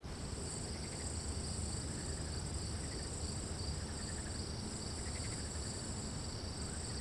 环境音
室外院子3.wav